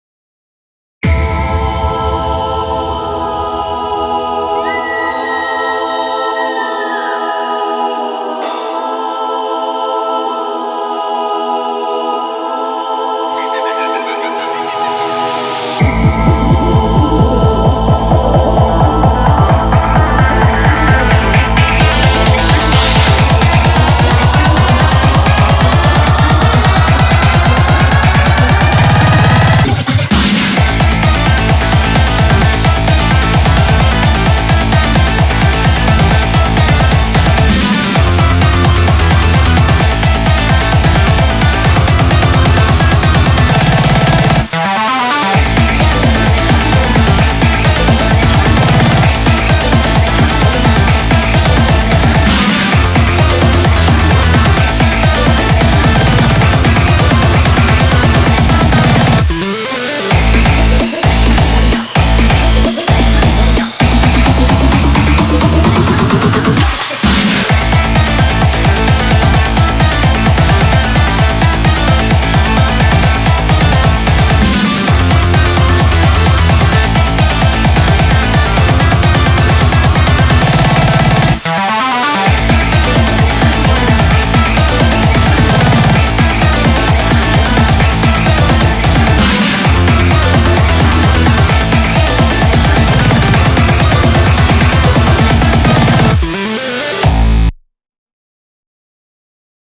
BPM130-520
Audio QualityMusic Cut